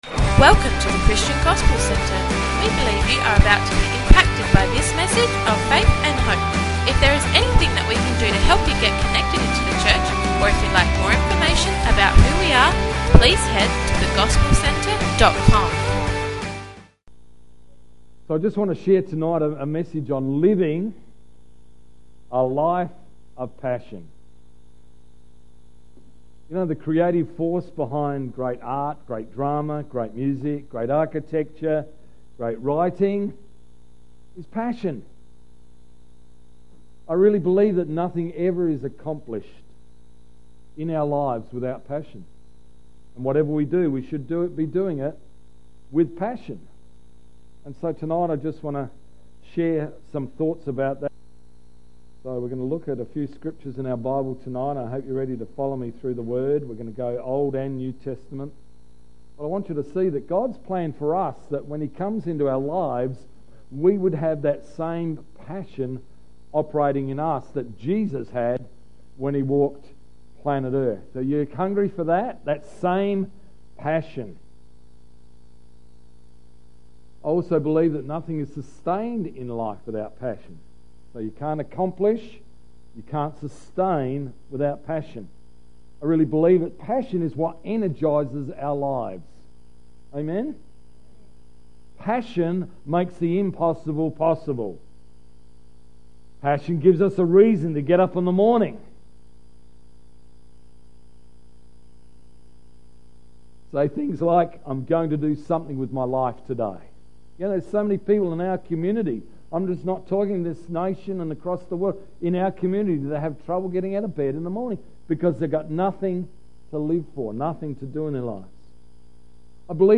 20th March 2016 – Evening Service